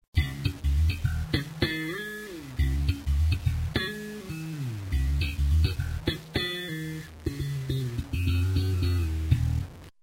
Electric bass (finger)
Bass.mp3